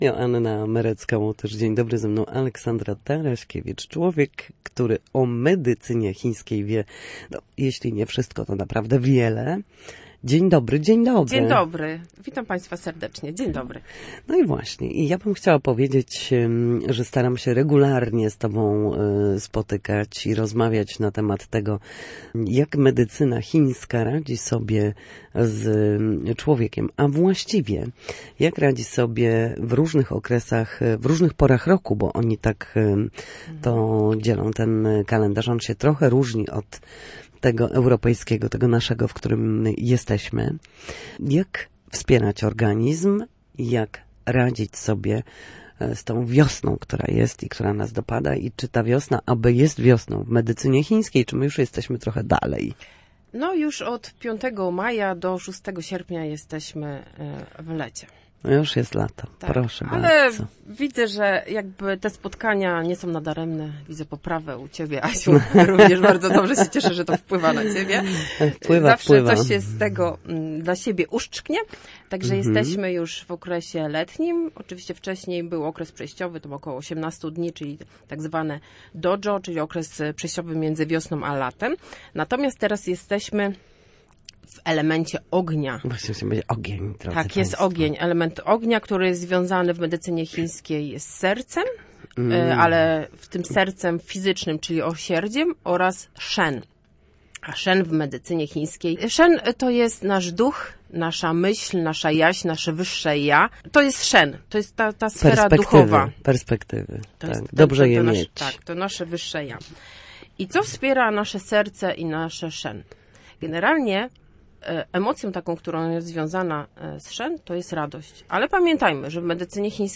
Jak zadbać o siebie latem? Medycyna chińska w praktyce. Rozmowa w audycji „Na zdrowie”
W każdą środę w popołudniowym Studiu Słupsk Radia Gdańsk rozmawiamy o tym, jak wrócić do formy po chorobach i urazach.